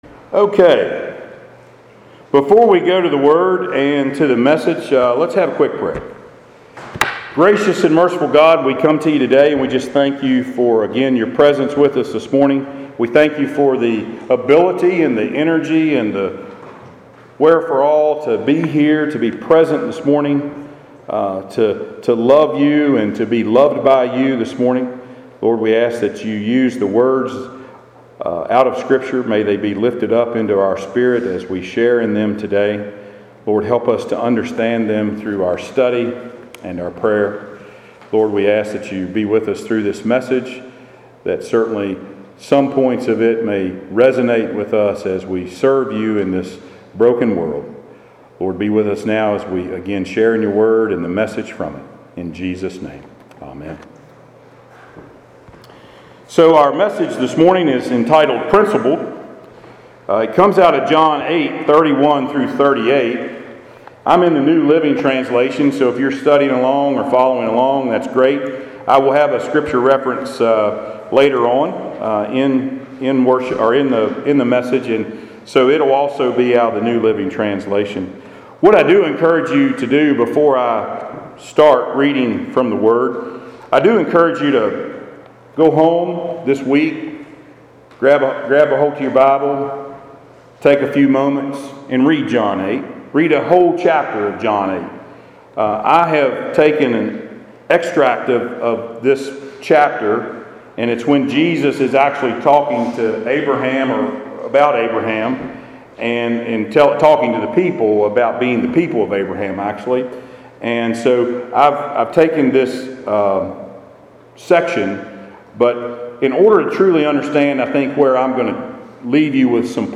Passage: John 8:31-38 Service Type: Sunday Worship